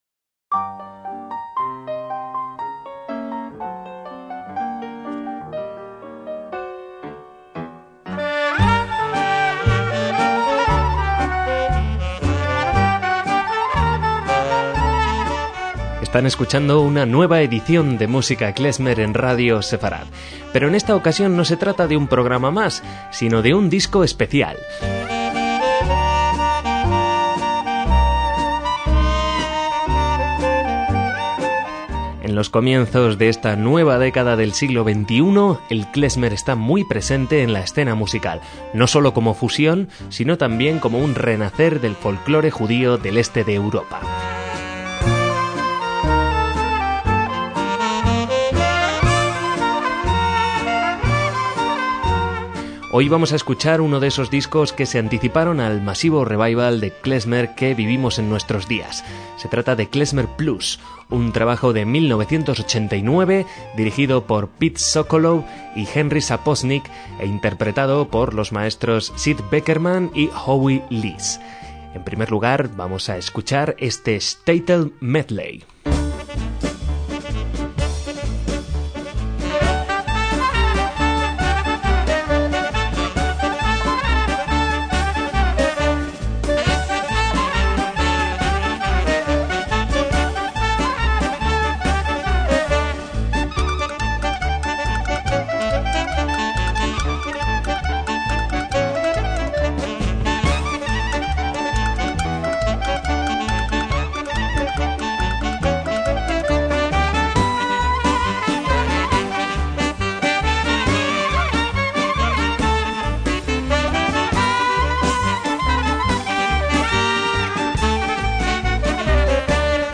sexteto
clarinetista
saxo tenor
bajo
batería
piano
trompeta